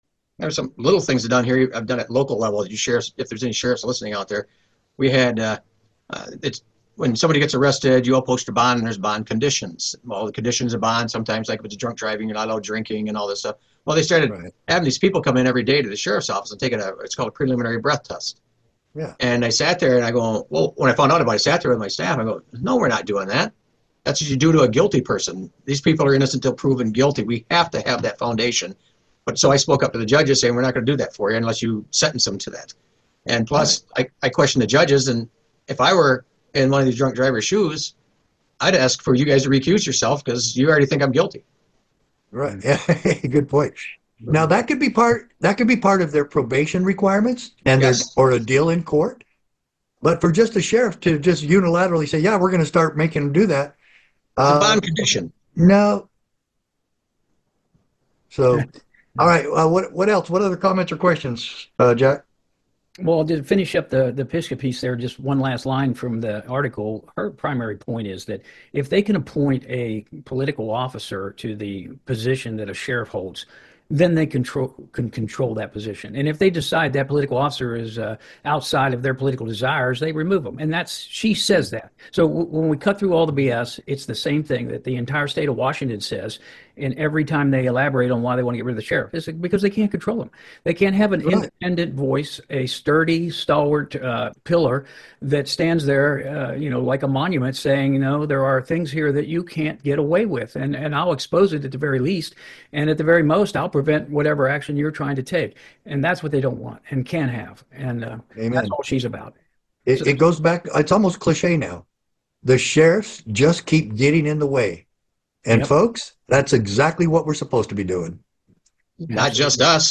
➡ The CSPOA discussion revolves around the role of sheriffs and their independence from political influence.